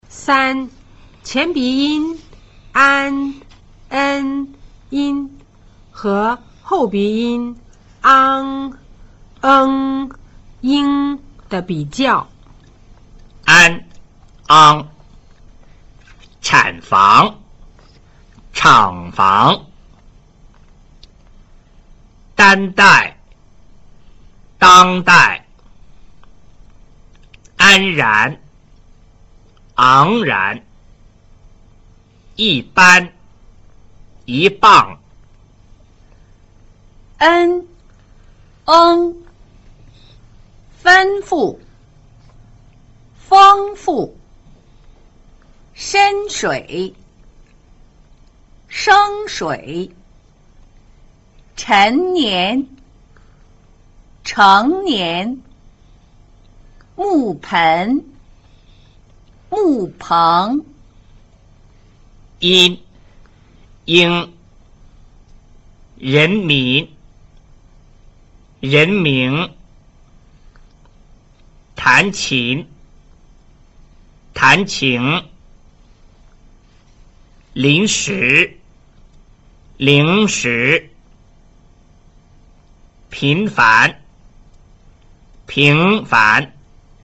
3 前鼻音 an  en  in 舌後鼻音  ang   eng   ing 的比較